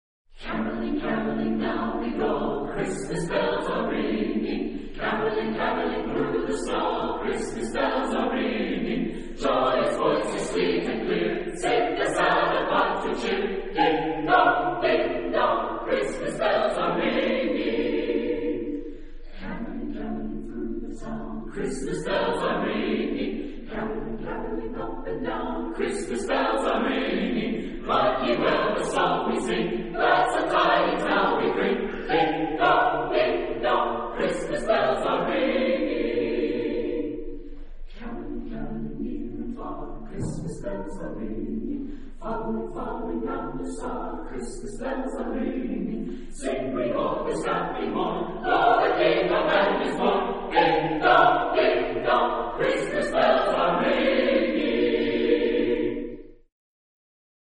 SATB (4 voices mixed) ; Close score.
Christmas carol.
Type of Choir: SATB (4 mixed voices )
Tonality: B flat major